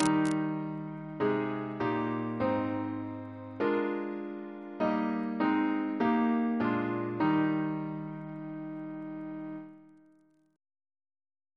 Single chant in E♭ Composer: James Turle (1802-1882), Organist of Westminster Abbey Reference psalters: ACB: 293